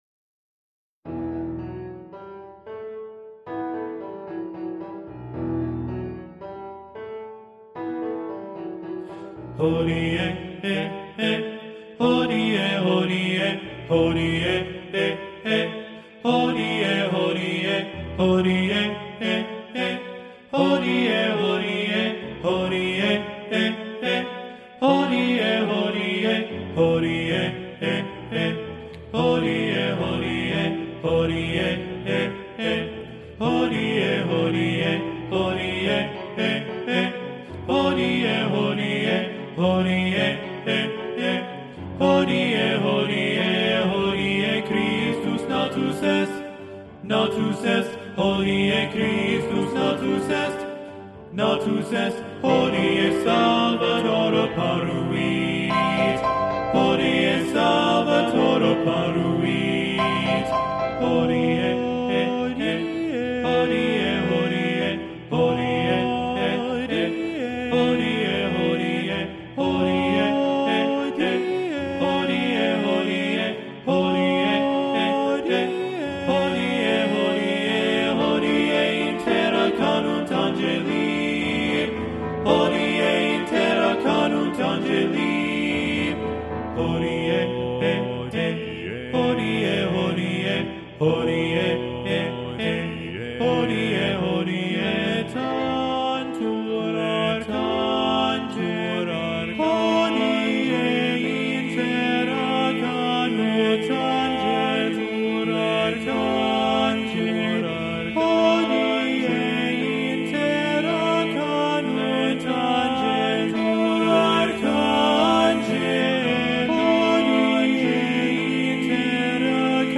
Voicing: TBB and Piano